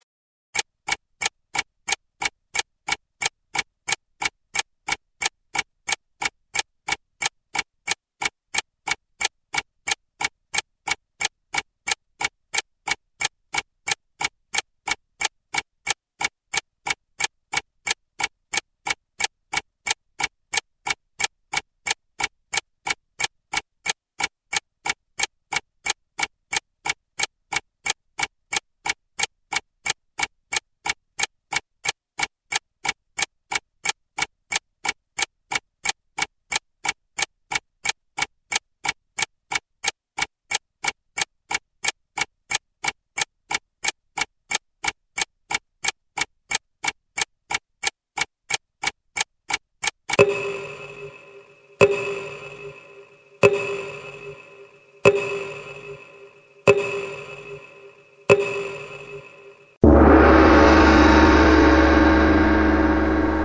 Звуки таймера
Звуки таймера на 1 минуту 60 секунд